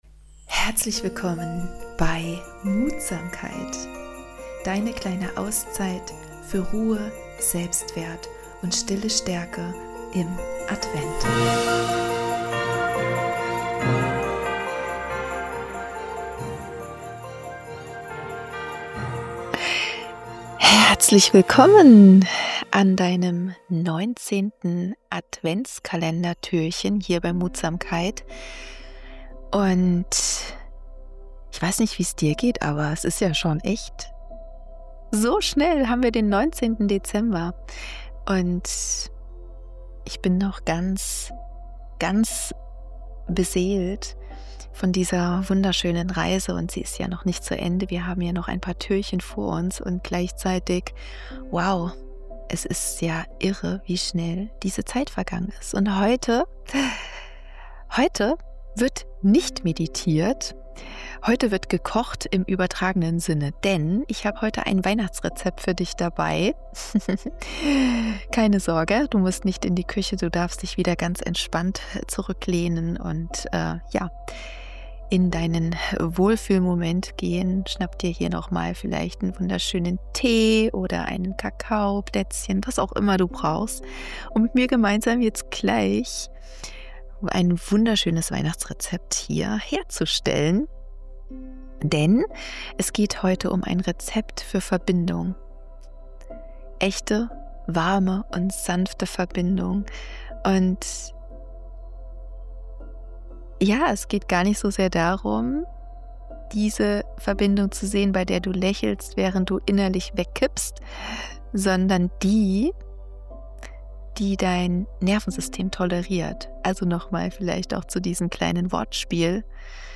Eine humorvolle, warme und ehrliche Adventsübung erwartet dich. In dieser Folge erschaffen wir gemeinsam ein „Rezept für Verbindung“ mit Ehrlichkeit, Mut, Selbstwert, Humor, Grenzen und Wärme.